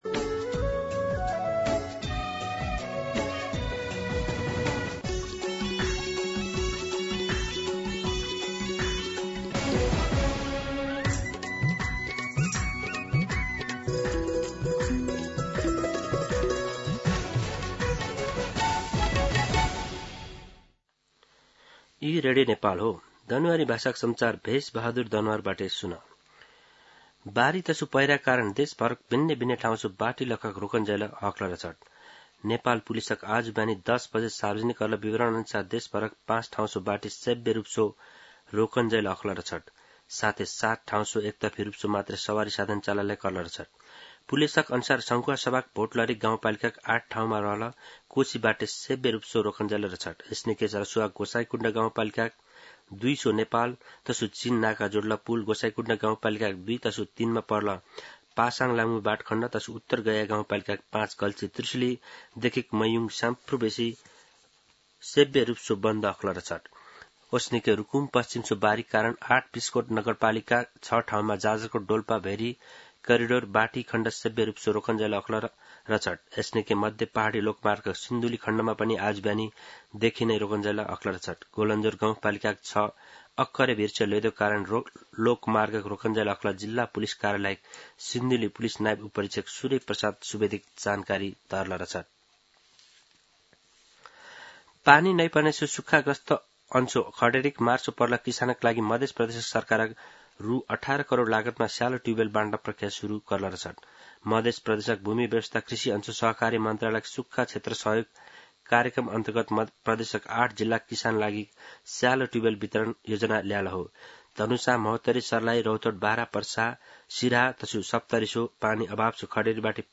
दनुवार भाषामा समाचार : १७ साउन , २०८२
Danuwar-News-1.mp3